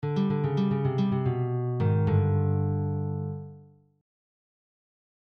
Blues Exercises > Blues ending
Blues+ending.mp3